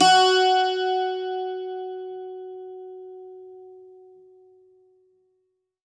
52-str08-bouz-f#3.wav